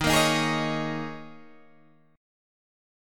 Eb7sus4 chord